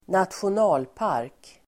Uttal: [²natsjon'a:lpar:k]